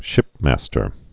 (shĭpmăstər)